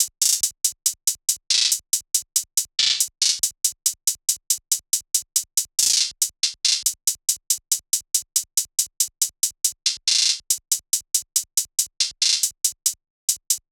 SOUTHSIDE_beat_loop_toast_hihat_140.wav